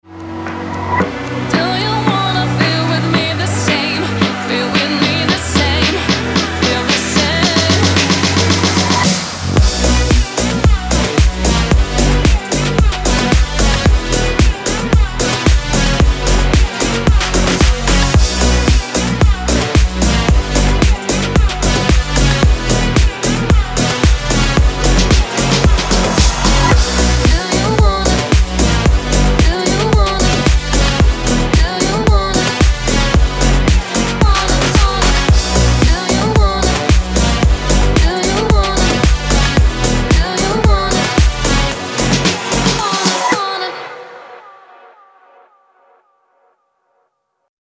ритмичные
громкие
deep house
dance
club
чувственные
красивый женский голос
Стиль: deep house